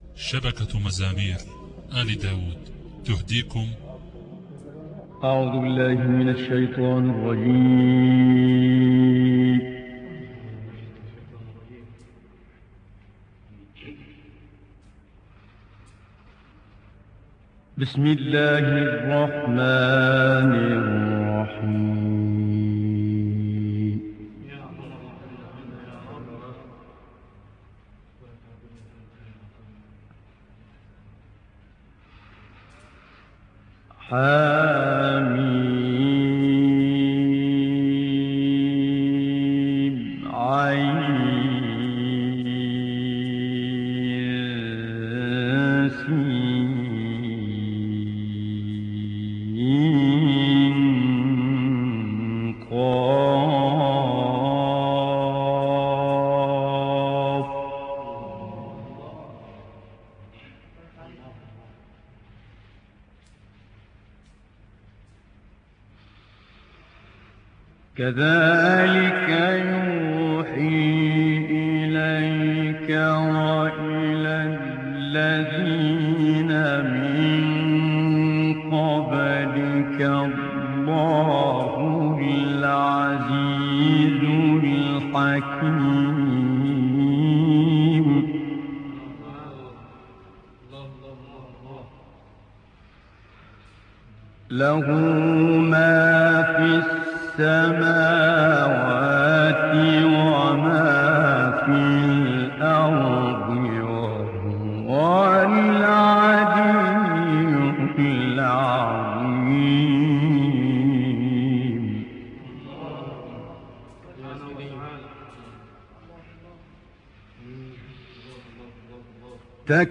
تسجيلات خارجية للشيخ سيد متولي